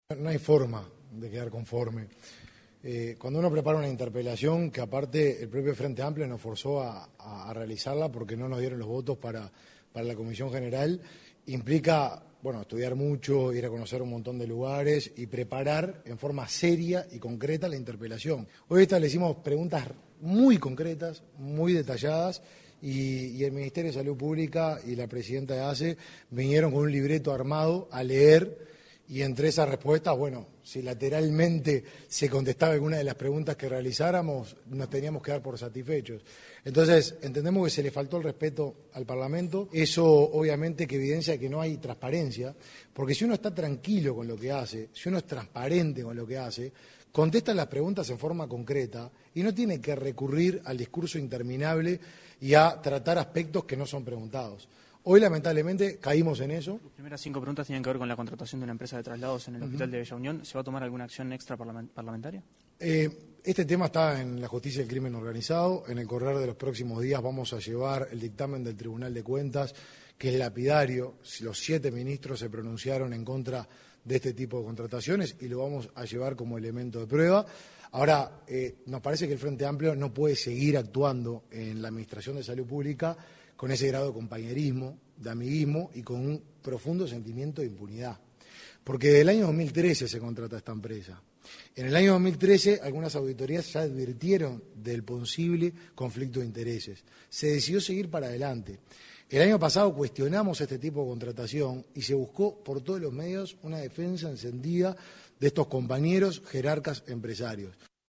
En rueda de prensa, Lema contó que no quedó conforme con los dichos del ministro y anunció que presentará el tema ante la Justicia: